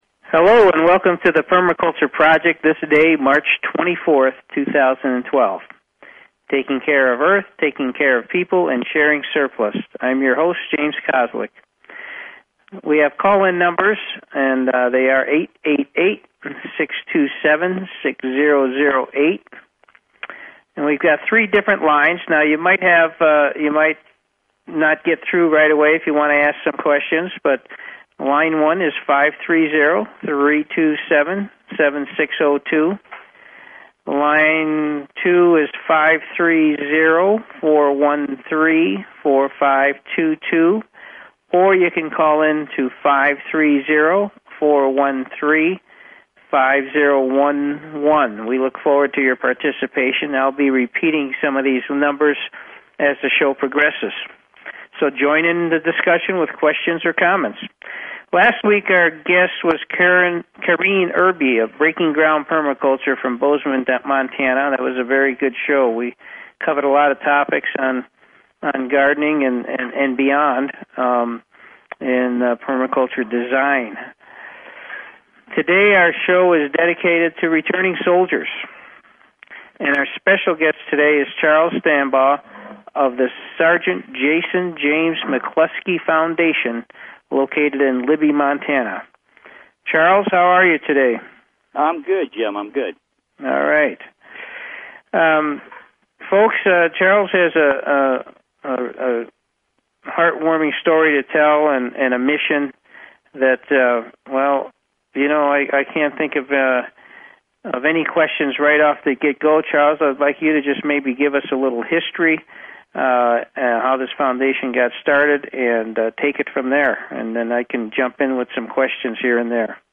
Talk Show Episode, Audio Podcast, Permaculture_Project and Courtesy of BBS Radio on , show guests , about , categorized as